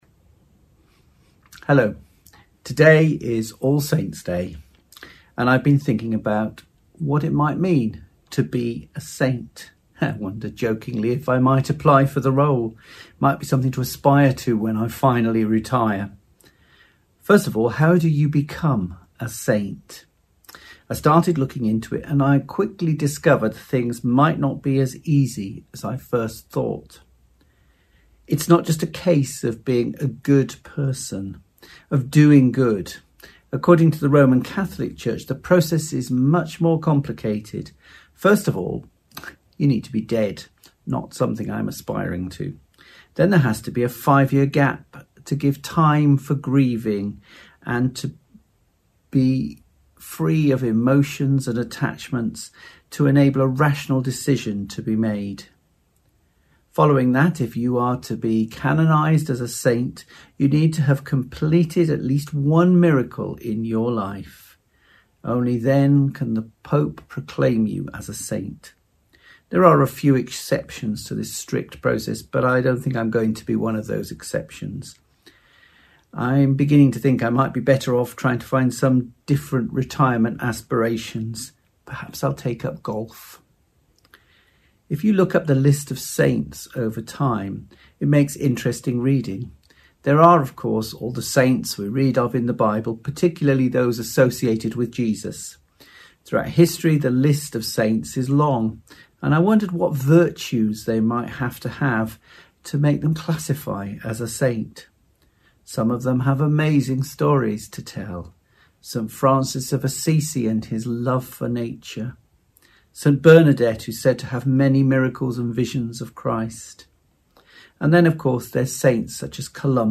latestsermon.mp3